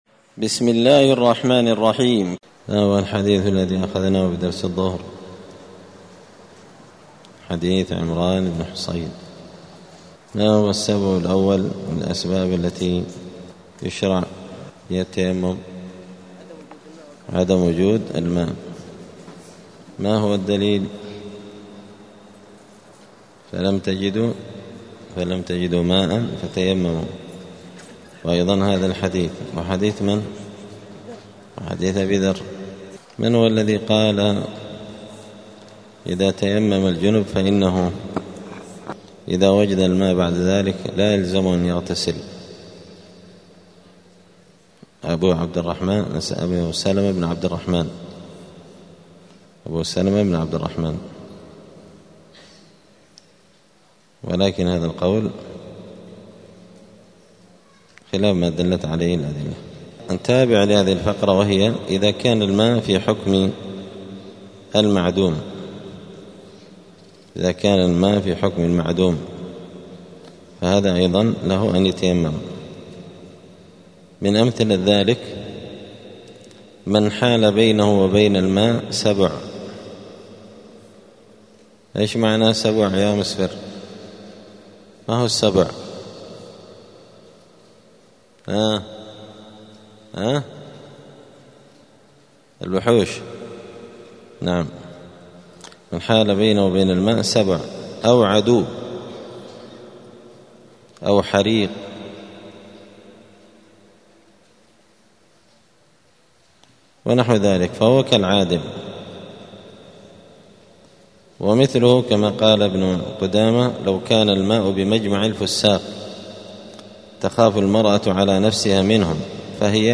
دار الحديث السلفية بمسجد الفرقان قشن المهرة اليمن
*الدرس الثامن والثمانون [88] {باب صفة التيمم الأسباب التي يشرع بها التيمم}*